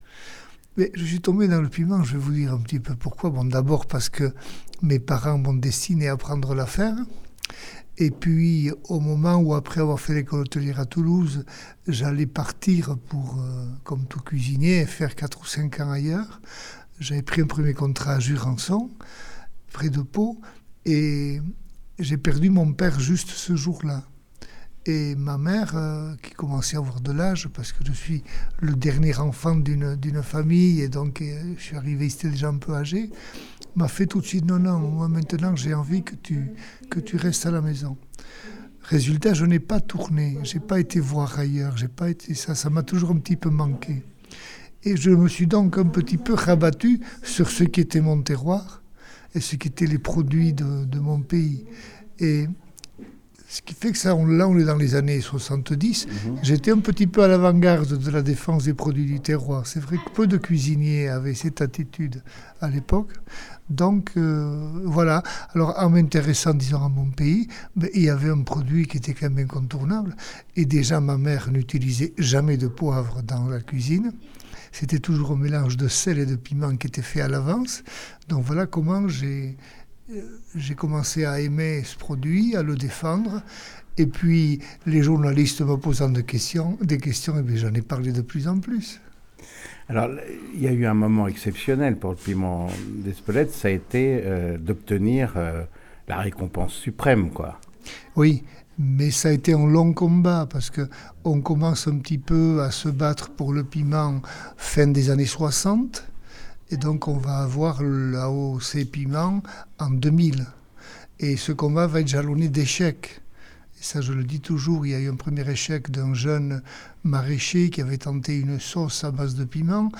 André Darraïdou, m’accueille dans son fameux hôtel Euzkadi, désormais l’unique hôtel-restaurant d’Espelette.
darraidou-andre-chef-euzkadi-espelette-portrait.mp3